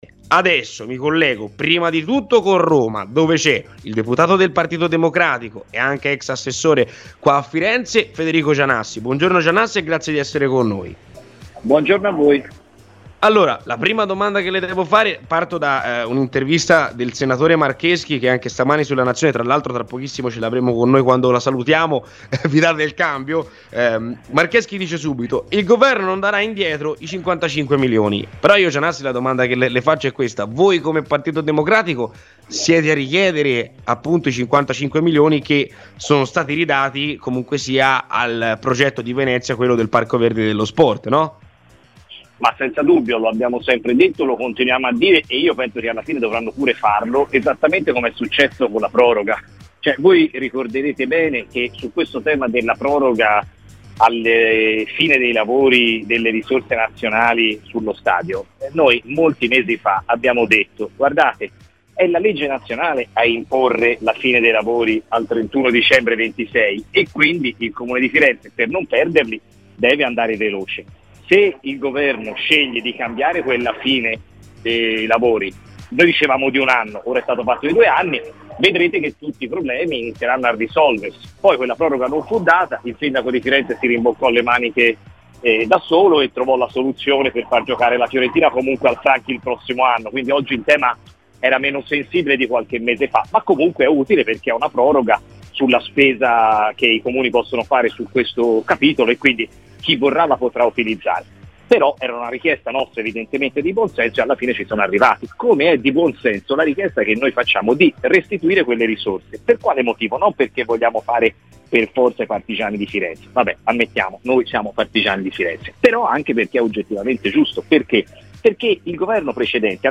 Federico Gianassi, Deputato Pd, è intervenuto a Radio FirenzeViola durante "C'è polemica" per rispondere alle ultime dichiarazioni del senatore di Fratelli d'Italia Paolo Marcheschi sul tema stadio Franchi, soprattutto sulla dichiarazione del collega che, alla Nazione, ha ribadito come il Governo non potrà recuperare i 55 milioni mancanti per il progetto: "Lo abbiamo sempre detto e l'abbiamo continuato a dire.